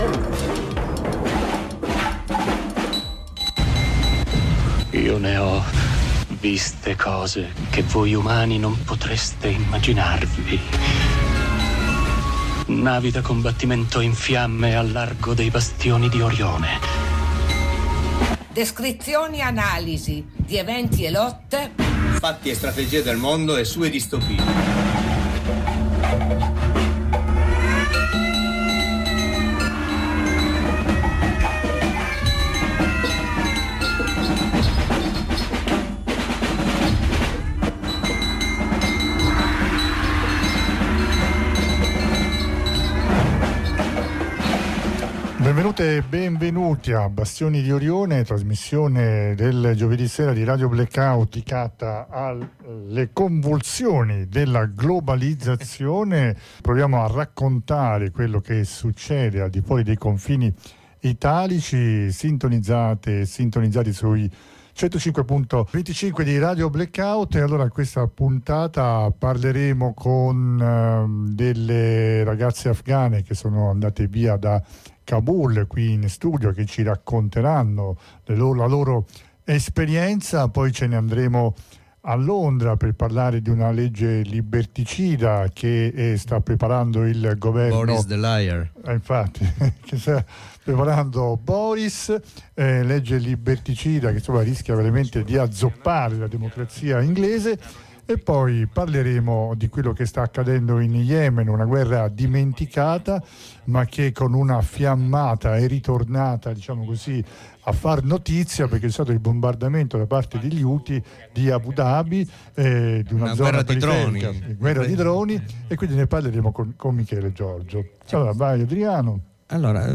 oggi parliamo di Afganistan con l’incontro in radio con due ragazze
RAGAZZE-AFGANE.mp3